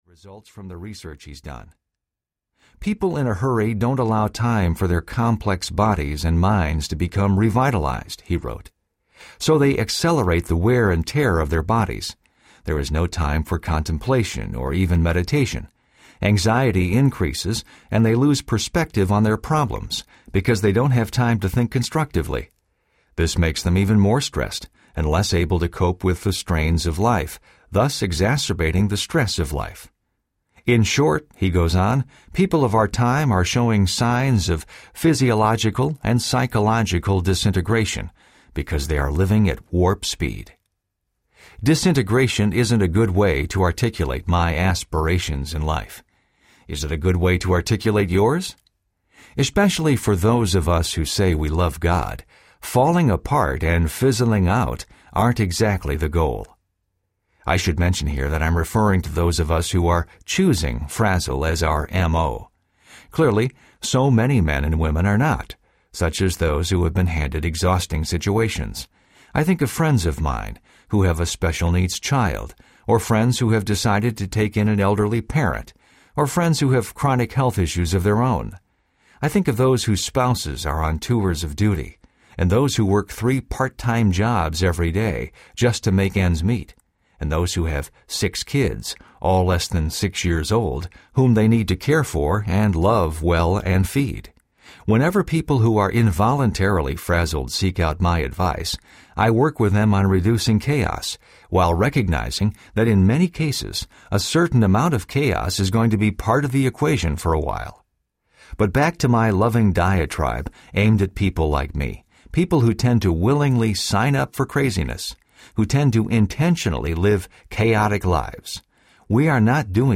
Addicted to Busy Audiobook
Narrator
5.25 Hrs. – Unabridged